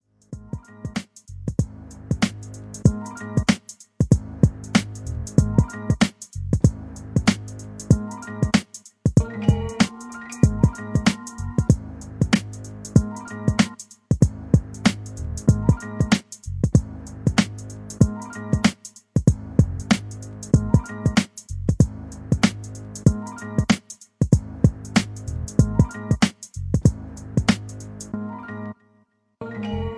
Action Scene Hip Hop. Aggressive